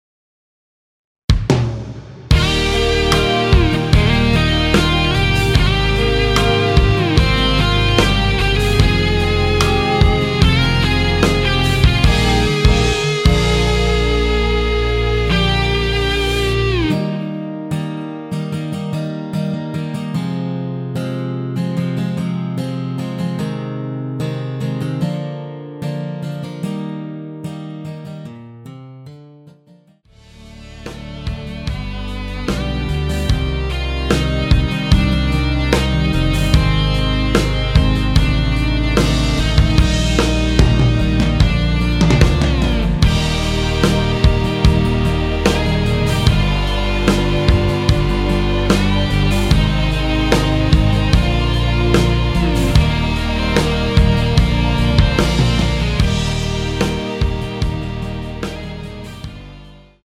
원키에서(-4)내린 MR입니다.
Eb
앞부분30초, 뒷부분30초씩 편집해서 올려 드리고 있습니다.